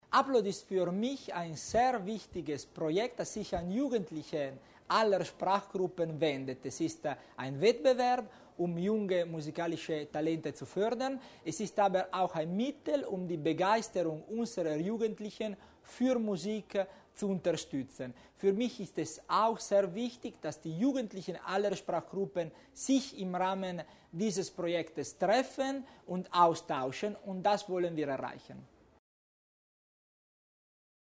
Landeshauptmannstellvertreter Tommasini stellt Upload 2010 vor